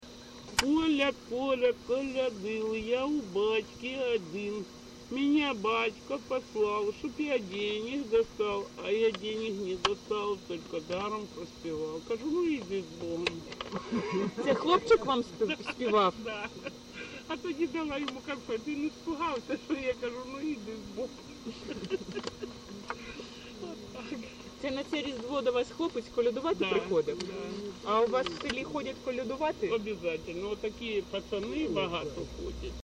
ЖанрКолядки
Місце записус. Клинове, Артемівський (Бахмутський) район, Донецька обл., Україна, Слобожанщина